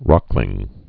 (rŏklĭng)